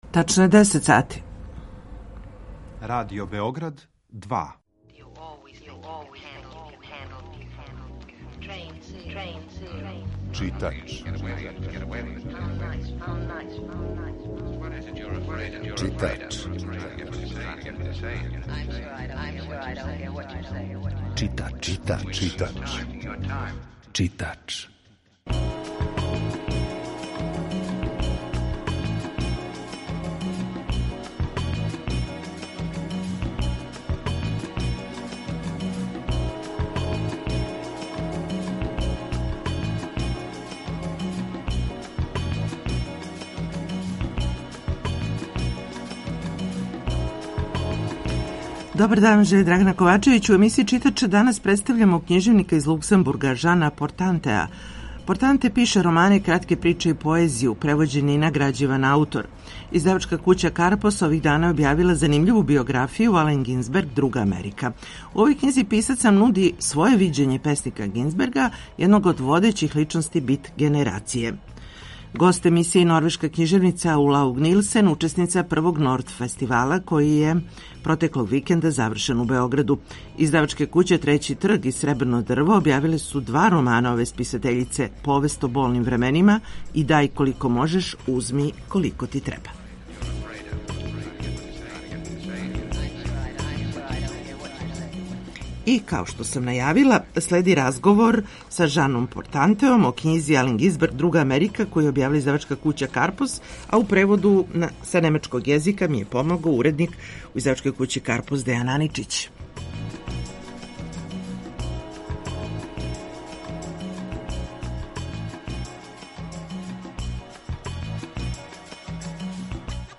У емисији Читач чућете разговор са књижевником из Луксембурга